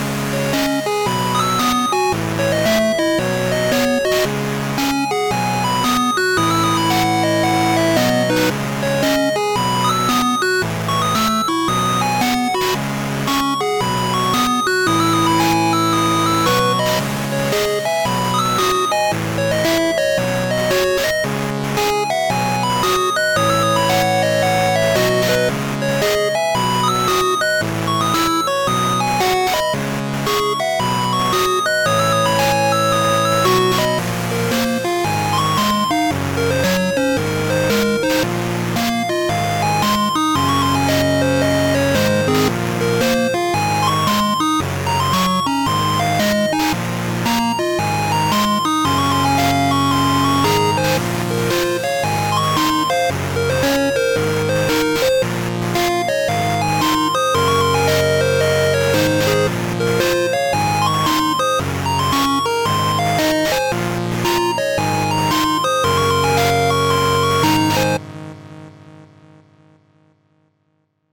Chiptune remix